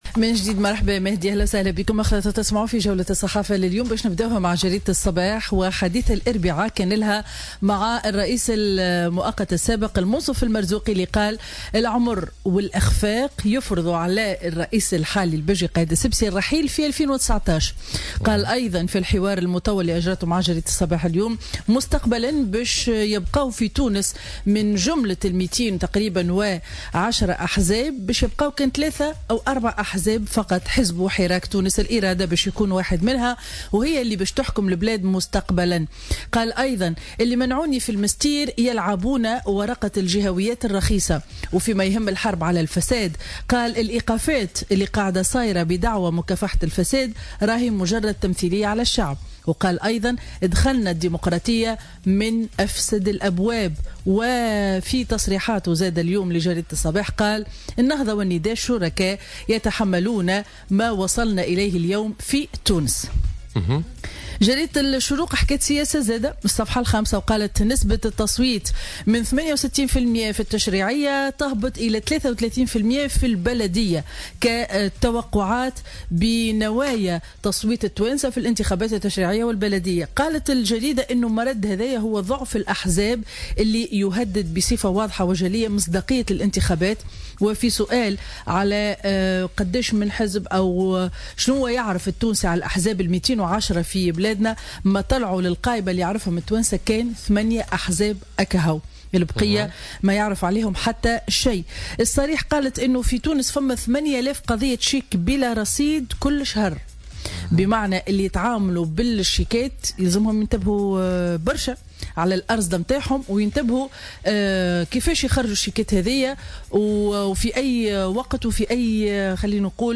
Revue de presse du mercredi 01 novembre 2017